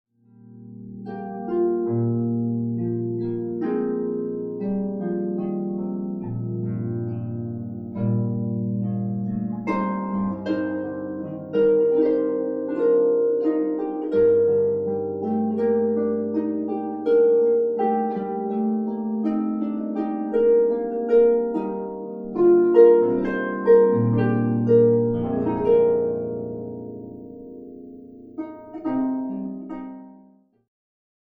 flute, harp and viola recording - American music
harp
Flute, Viola, Harp CD sacred music
harp solo